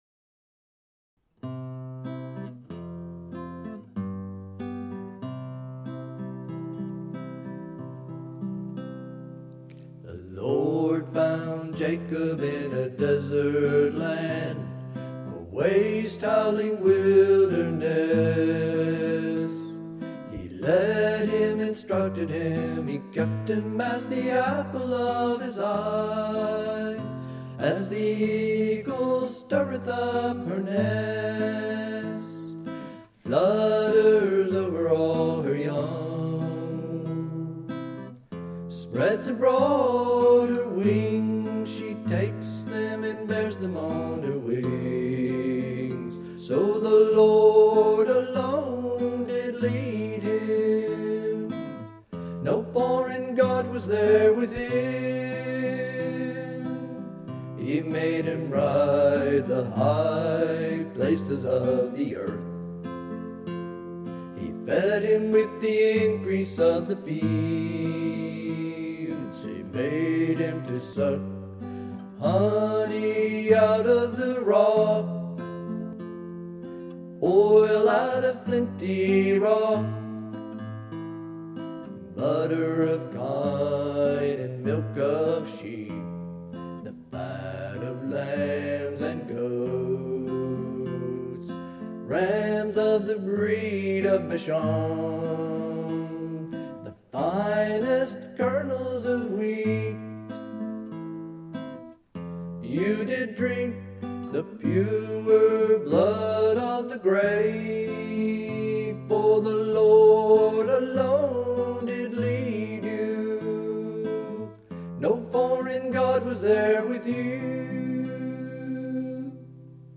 7:00 am to 8:00 am – Morning Worship, Prayer, Breaking of Bread – Learning “Song of Moses” Part I &